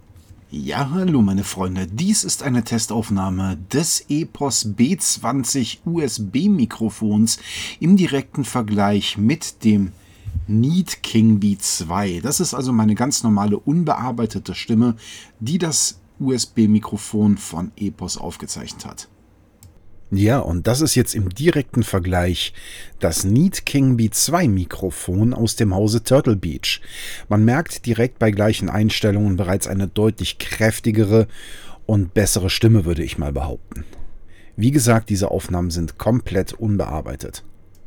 Ohne große Verstärkung und Audiobearbeitung haben wir so gut wie keinerlei hörbare Hintergrundgeräusche, kein rauschen, trotz günstigem Interface und billigen Kabeln.
… und Nein, das ist kein gesponsorter Artikel, wir werden auch nicht für diese Aussage bezahlt, aus diesem Grund hier gerne ein kurzer Vergleich:
Ihr hört direkt das sich die zweite Aufnahme deutlich besser anhört und das für komplett unbearbeiteten Ton, bei 1:1 den gleichen Einstellungen. Sprich das USB Mikrofon, das Beste das wir bisher hatten, war auf Nierencharakteristik eingestellt und die Eingangsverstärkung beider Geräte war gleich eingestellt.
Vergleich-EPOS-B20-NEAT-King-Bee-2.mp3